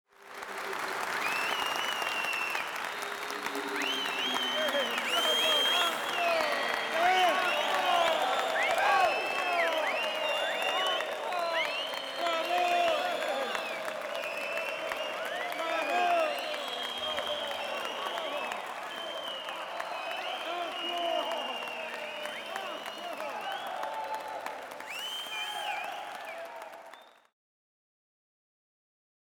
human
Crowd Large Of Rowdy Males Yelling